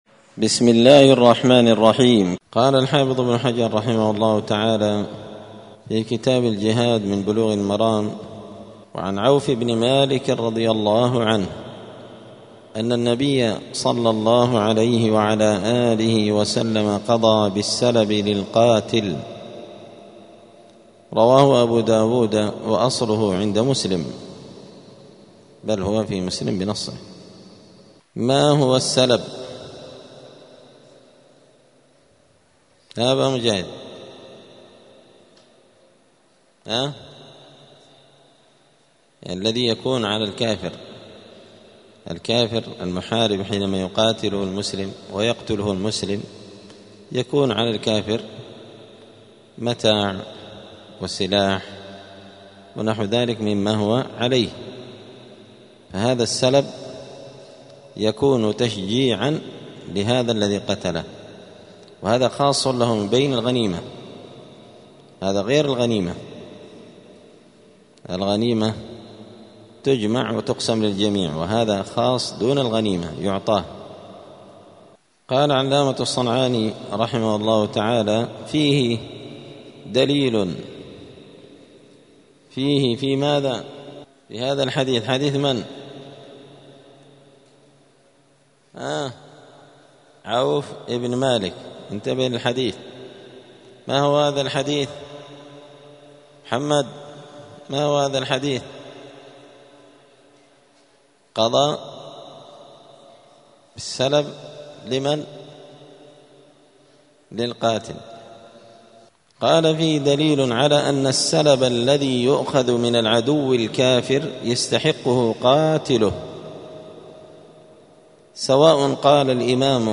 *الدرس الرابع عشر (14) {باب ﻣﻦ ﻗﺘﻞ ﻗﺘﻴﻼ ﻓﻠﻪ ﺳﻠﺒﻪ}*
دار الحديث السلفية بمسجد الفرقان قشن المهرة اليمن